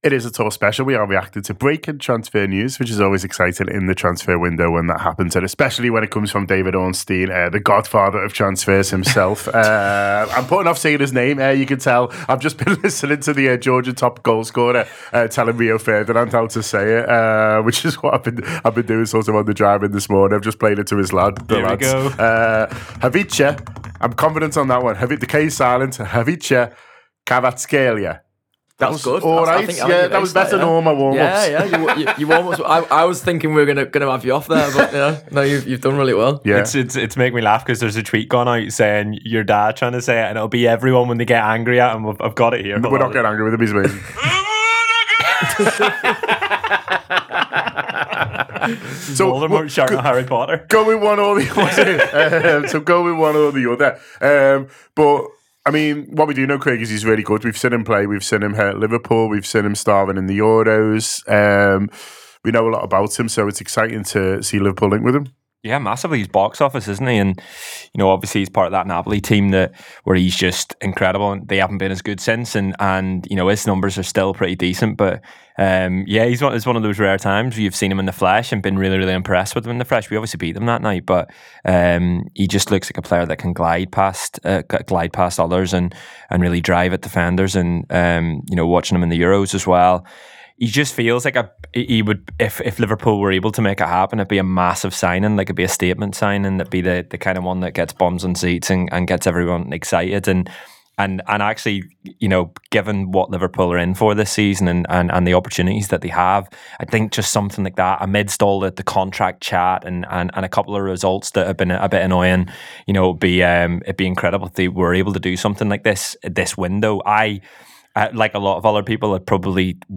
Below is a clip from the show – subscribe for more on Khvicha Kvaratskhelia and other Liverpool transfer news…